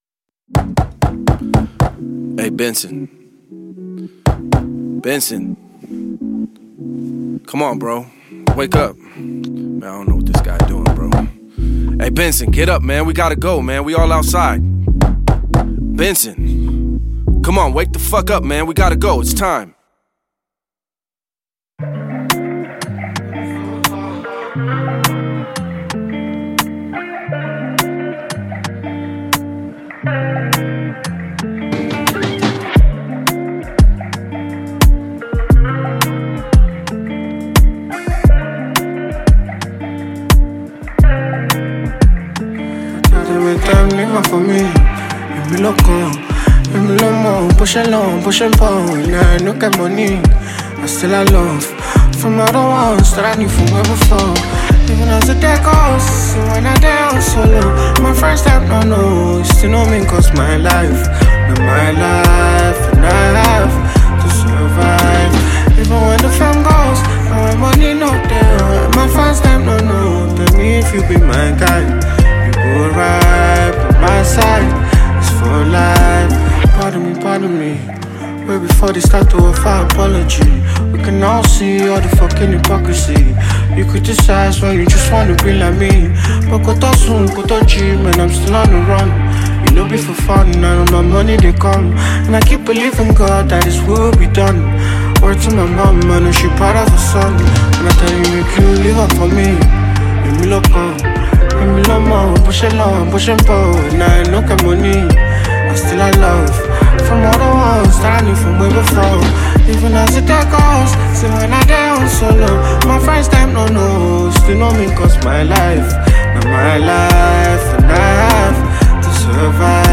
Noteworthy Nigerian singer and songwriter